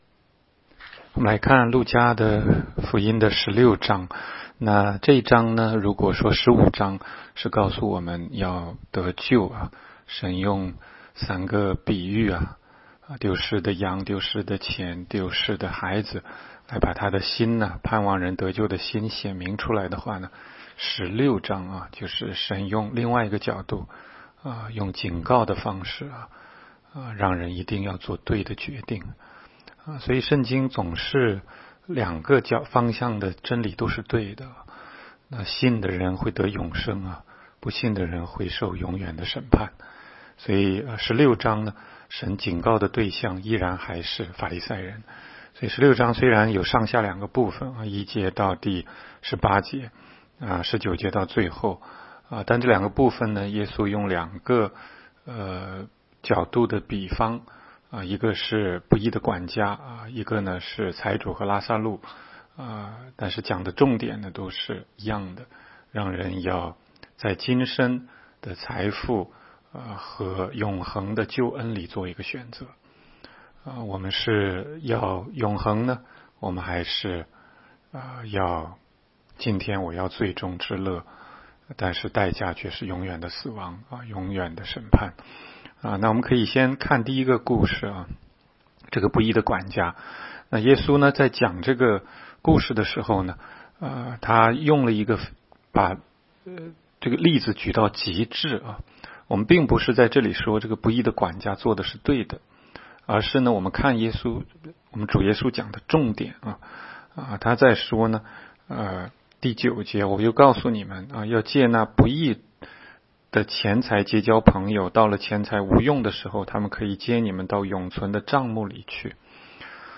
16街讲道录音 - 每日读经-《路加福音》16章